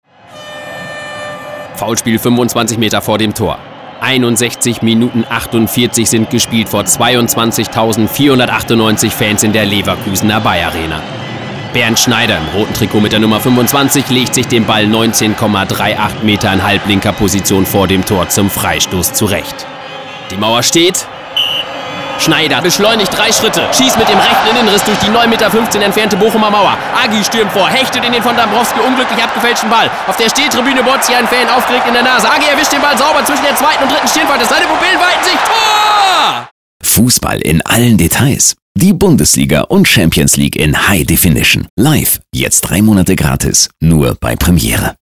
Junger, deutscher Sprecher mit einer noch unverbrauchten Stimme.
Kein Dialekt
Sprechprobe: Industrie (Muttersprache):
Young german voice over artist.